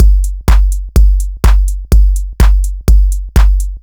• house - techno beat passage - Gm - 125.wav
A loop that can help you boost your production workflow, nicely arranged electronic percussion, ready to utilize and royalty free.
house_-_techno_beat_passage_-_Gm_-_125_9Fr.wav